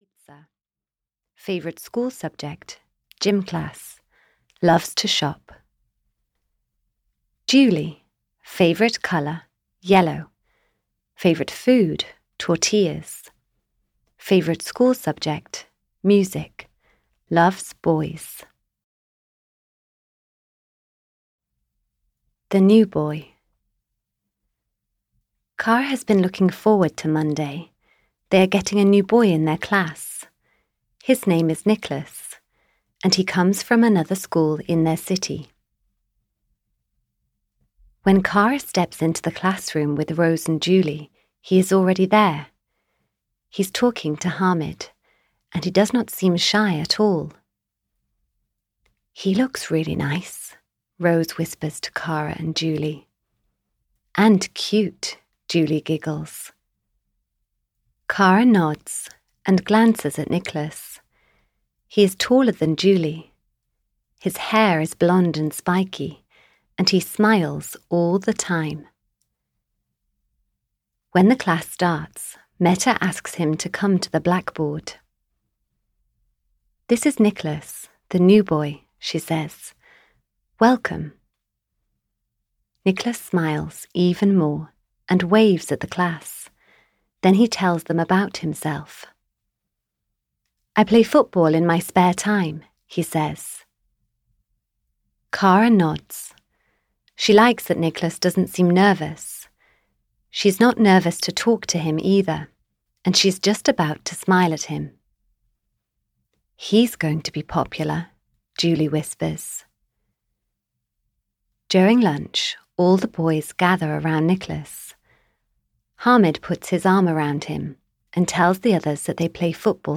K for Kara 11 - Are We Friends? (EN) audiokniha
Ukázka z knihy